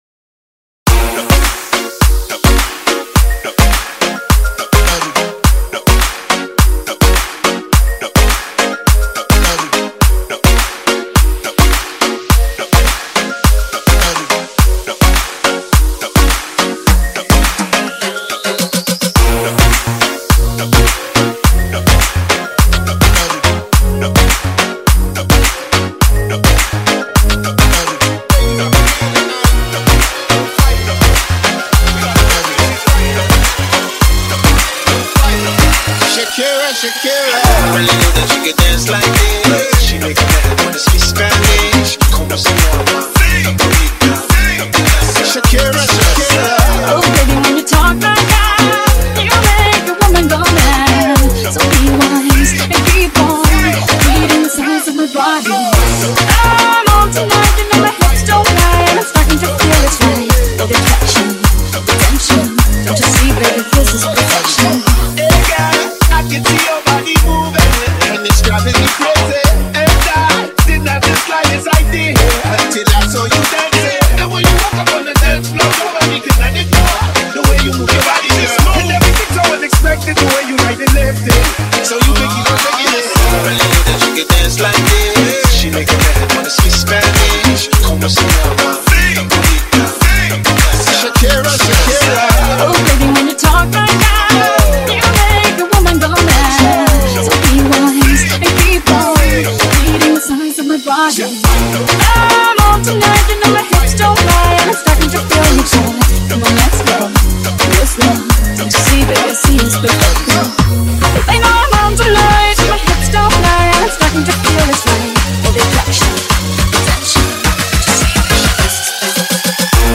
High quality Sri Lankan remix MP3 (2).